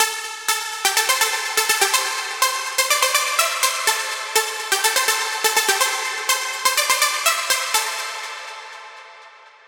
プラックのサウンドはアタックを弱めて思い切りサステインを伸ばしたのでアタック感のあるシンセパッドサウンドに、リードはサステインとディケイを短くしたのでノイズ感のあるプラックサウンドになりました。
Nexus-例-Saw→プラック.mp3